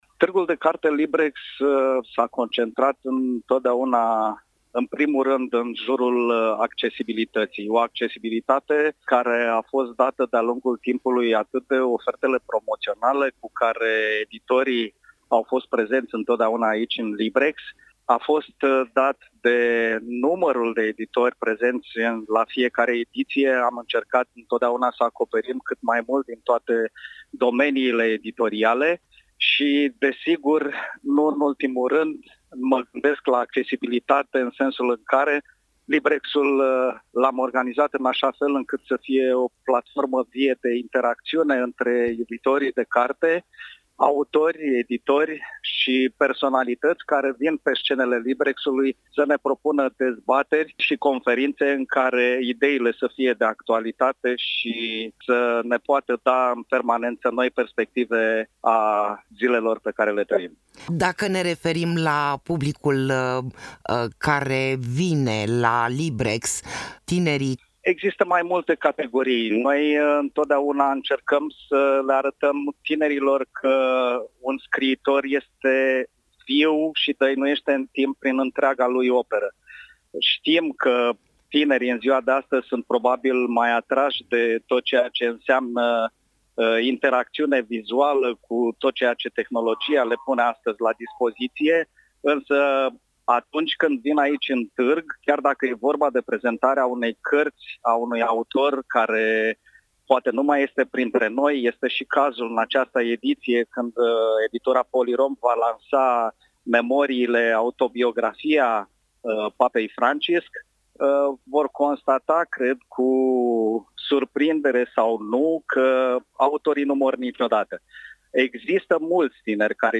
Interviu-Librex-Iasi.mp3